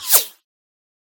whine_3.ogg